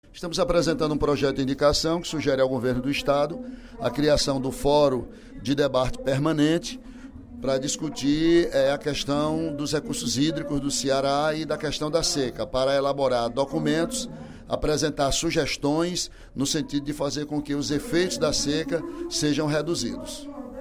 Durante o primeiro expediente da sessão plenária da Assembleia Legislativa desta quinta-feira (05/02), o deputado Ely Aguiar (PSDC) pediu apoio para o projeto de indicação que pretende apresentar após a formação das comissões técnicas. Segundo informou, o projeto proporá a criação de um fórum permanente de debate sobre os recursos hídricos e reuniria, além de parlamentares, também representantes e técnicos de todos os órgãos do Governo do Ceará.